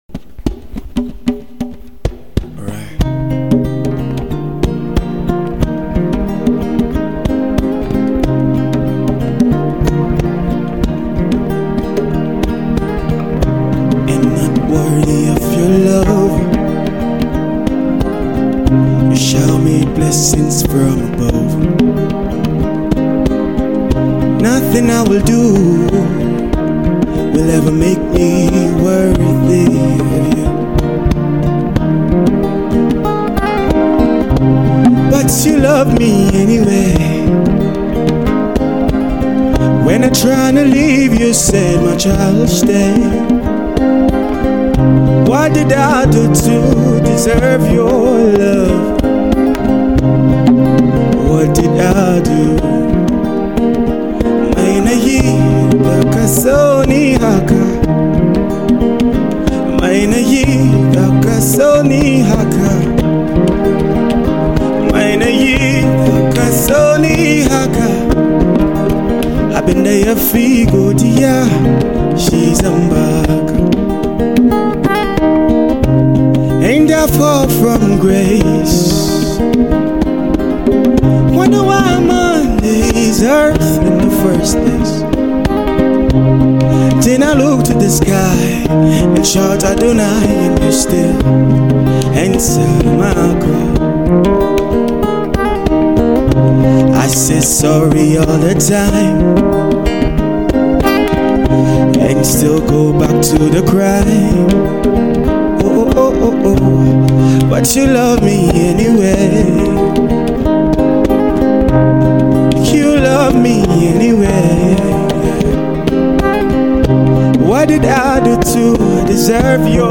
Download the Live Session here